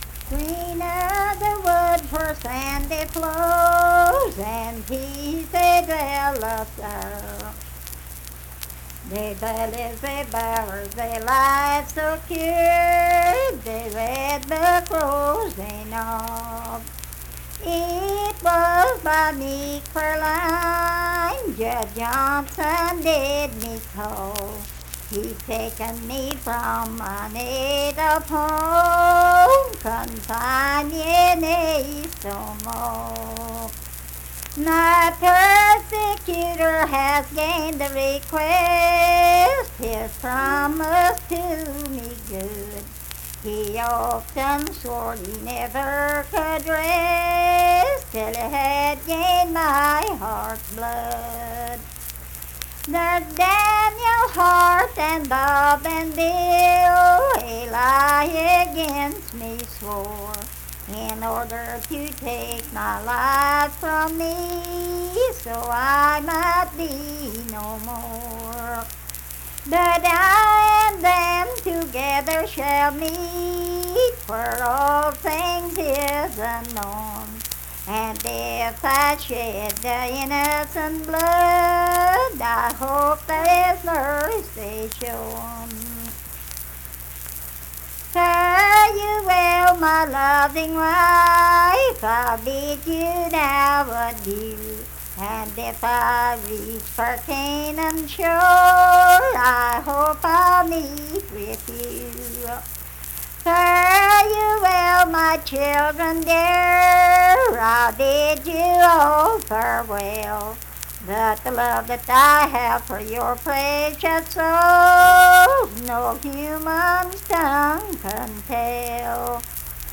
Unaccompanied vocal music performance
Verse-refrain 7(4).
Voice (sung)